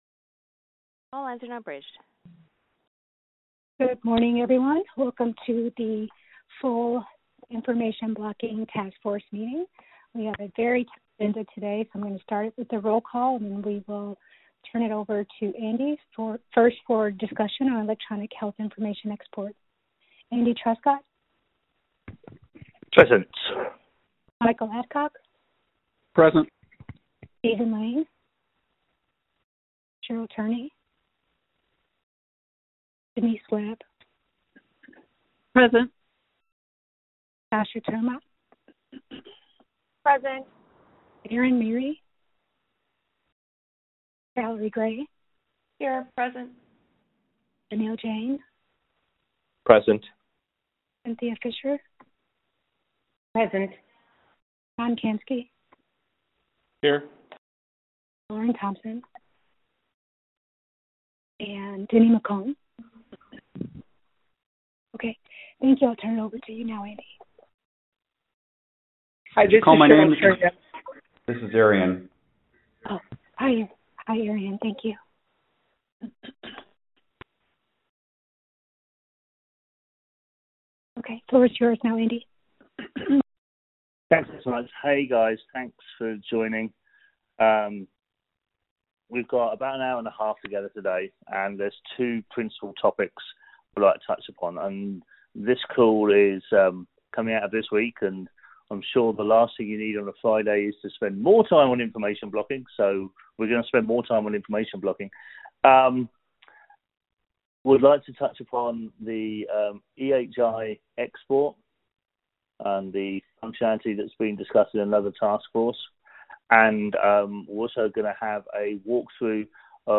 2019-03-22_IACC_VirtualMeeting_Audio